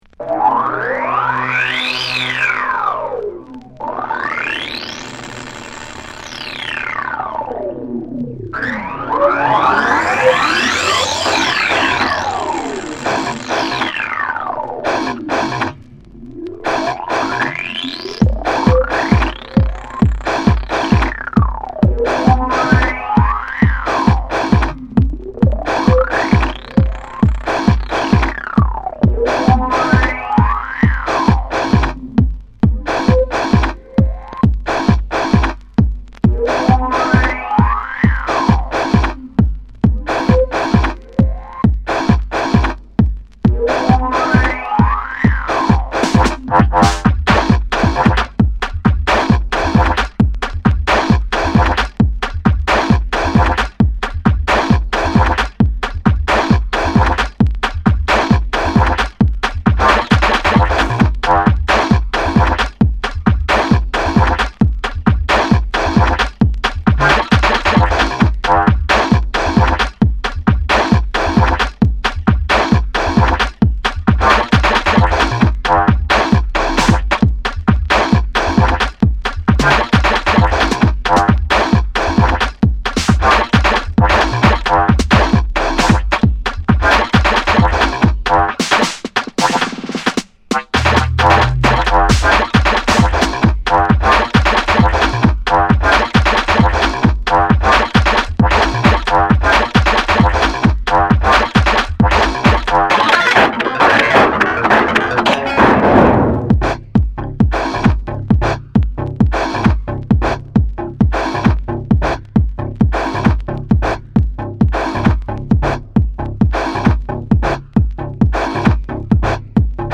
この時代ならではの音使いや豪快な展開、それでいて職人気質すぎる几帳面さも垣間見えるのが長く支持される所以なんでしょうね。